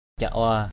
/ca-ʊa/